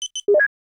openHologram.wav